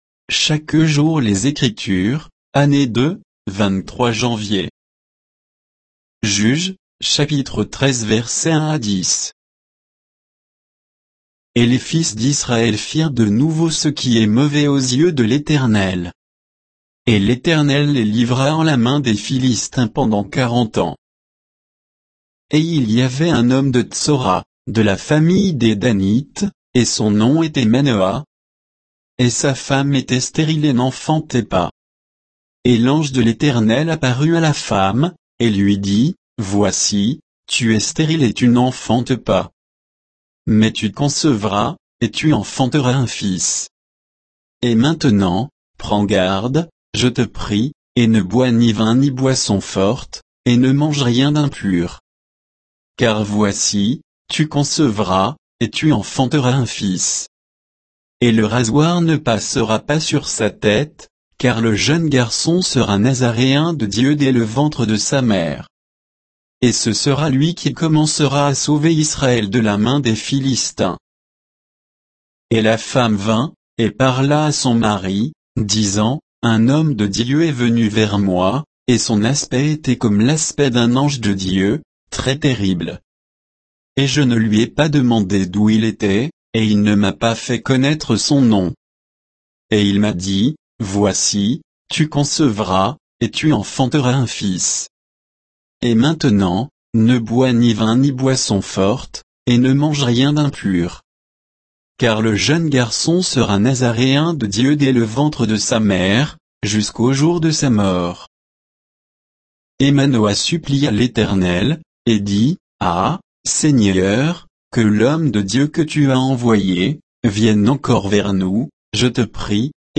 Méditation quoditienne de Chaque jour les Écritures sur Juges 13